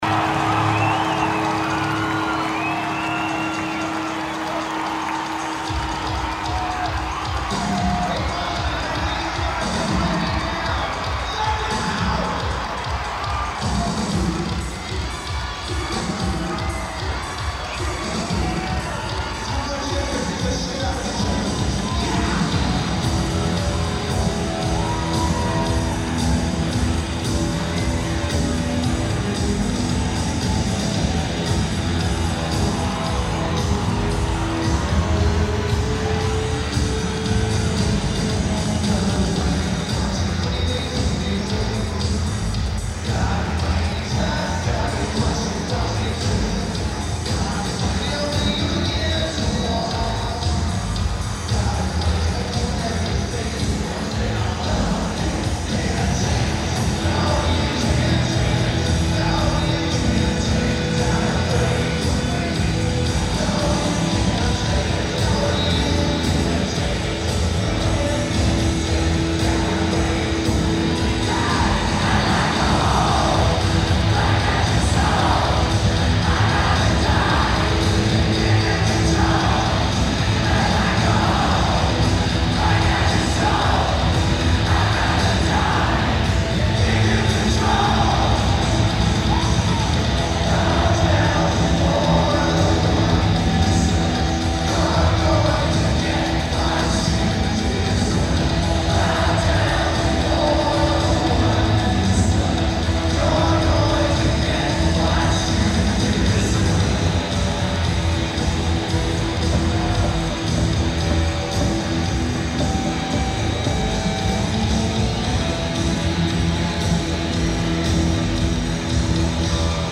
Boston Garden
The hiss noise floor is really low and sounds way clear.